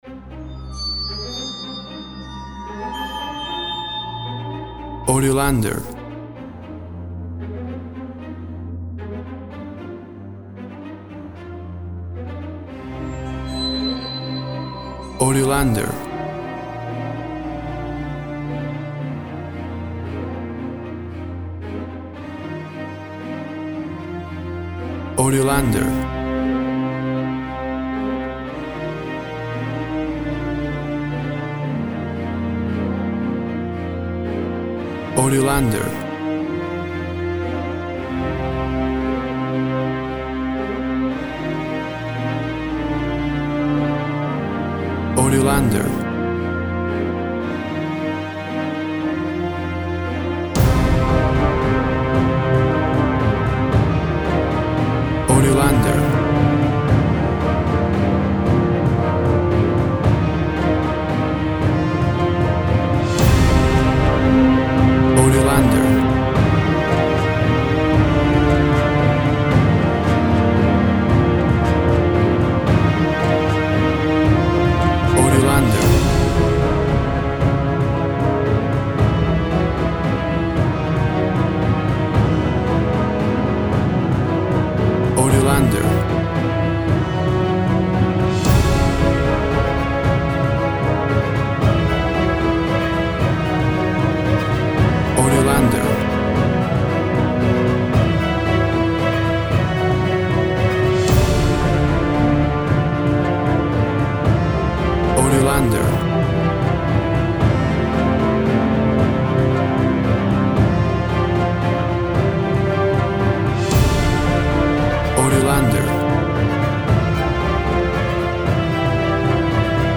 An epic cinematic track
Tempo (BPM) 115